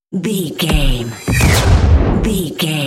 Sci fi shot whoosh to hit
Sound Effects
Atonal
futuristic
intense
woosh to hit